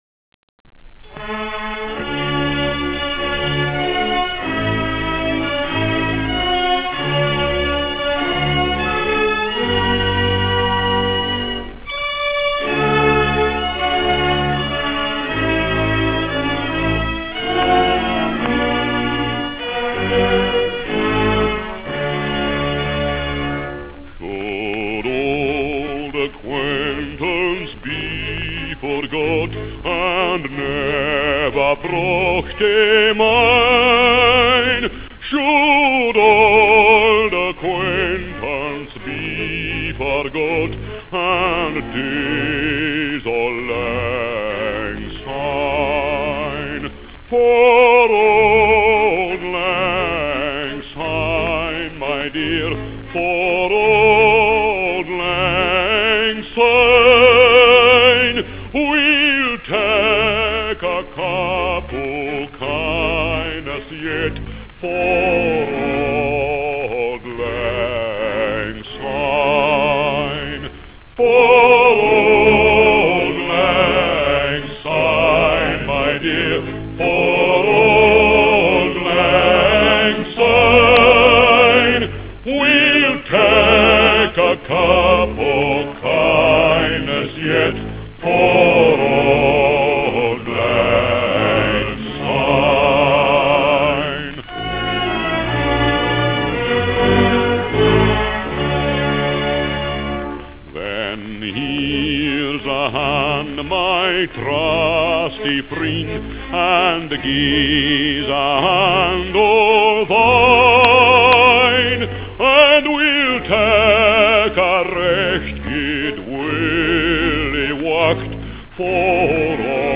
Sung by Peter Dawson
52aAuldLangSynePeterDawson.wav